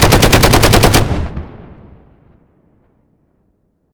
machineout.ogg